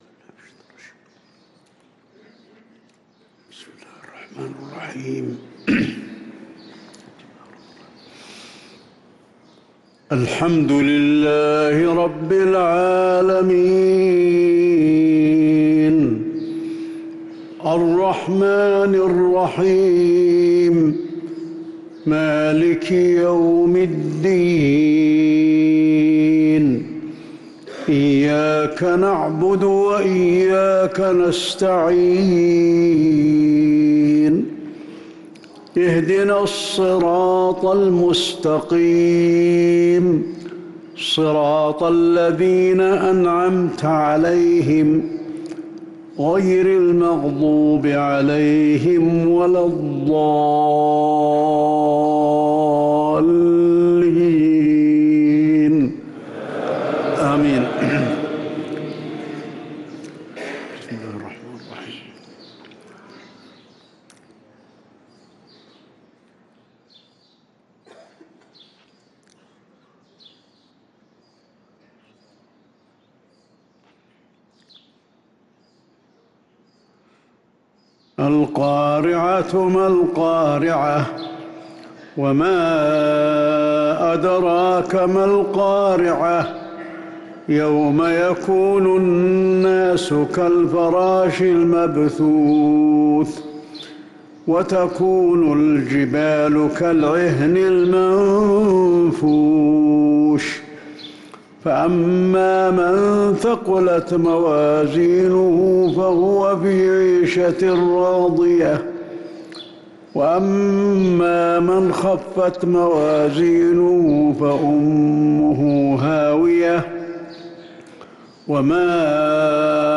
صلاة المغرب للقارئ علي الحذيفي 4 رمضان 1444 هـ
تِلَاوَات الْحَرَمَيْن .